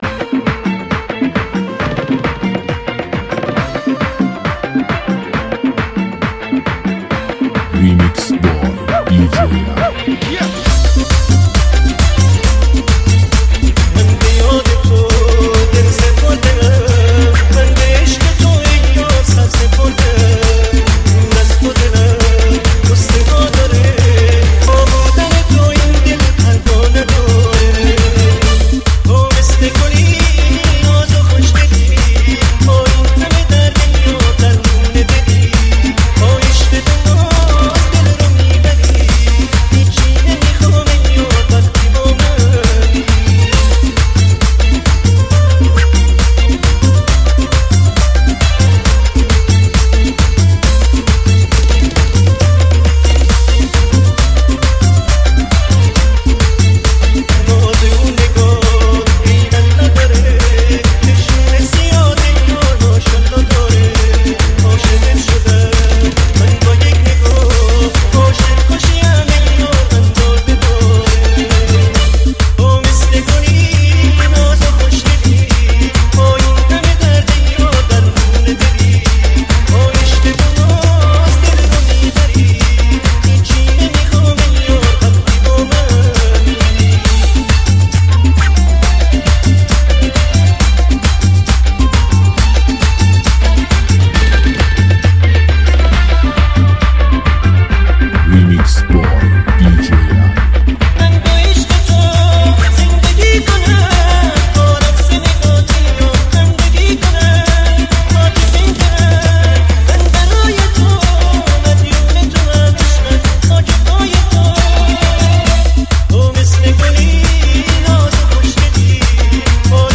آهنگی خاطره‌انگیز و نوستالژیک در قالبی جدید!
با ریتمی پرانرژی میکس شده